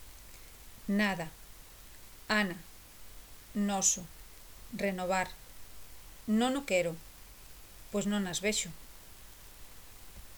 /n/